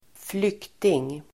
Uttal: [²fl'yk:ting]